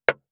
459,切る,包丁,厨房,台所,野菜切る,咀嚼音,ナイフ,調理音,まな板の上,料理,
効果音